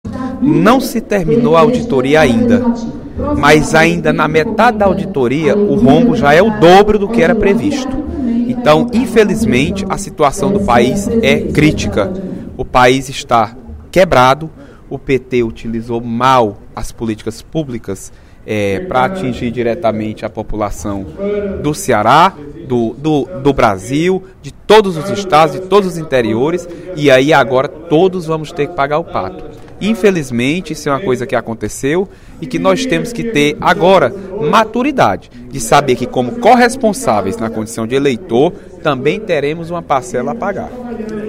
O deputado Leonardo Araújo (PMDB) fez pronunciamento nesta sexta-feira (20/05), no primeiro expediente da sessão plenária, para abordar o afastamento da presidente Dilma Rousseff. De acordo com o parlamentar, o governo petista “deixou um grande rombo” nas contas nacionais.